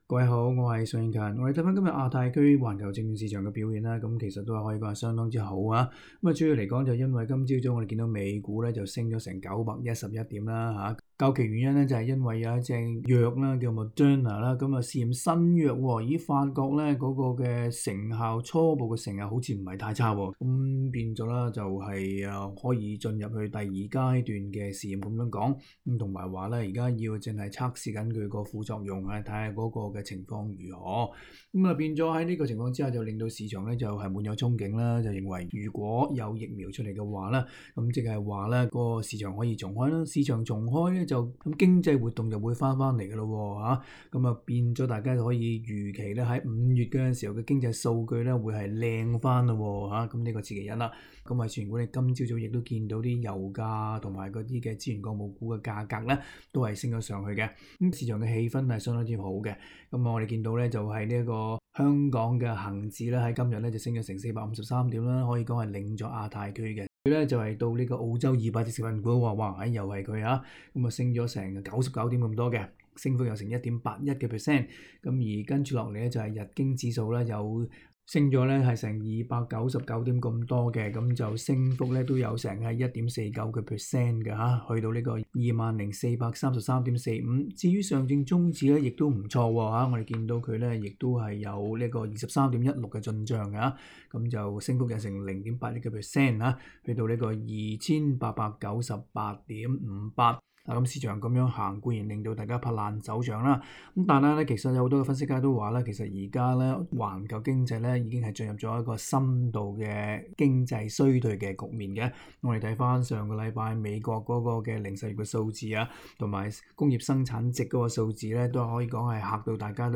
（详情请收听今天的访问内容）。